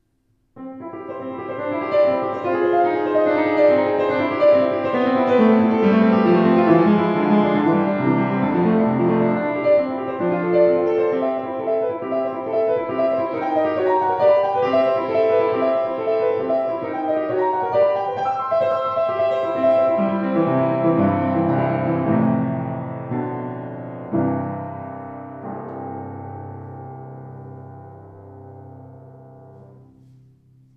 Sauter 115 gebraucht schwarz
Gestaltungsfähiger Klang, angenehme, flüssige Spielart.